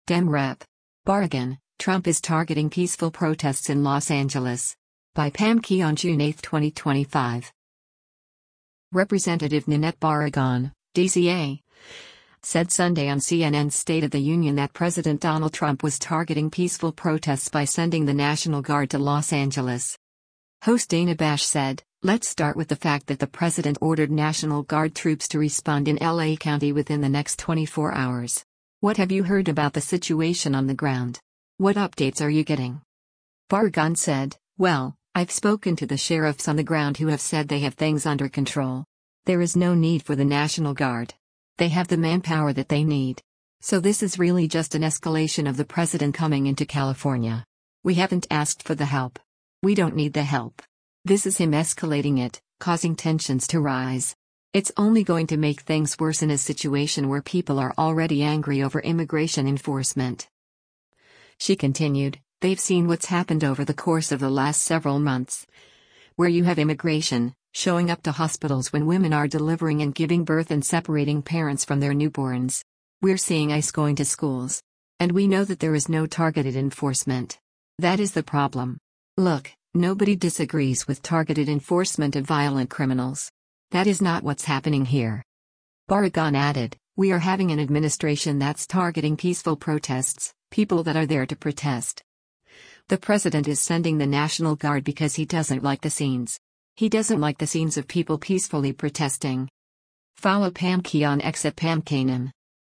Representative Nanette Barragán (D-CA) said Sunday on CNN’s “State of the Union” that President Donald Trump was “targeting peaceful protests” by sending the National Guard to Los Angeles.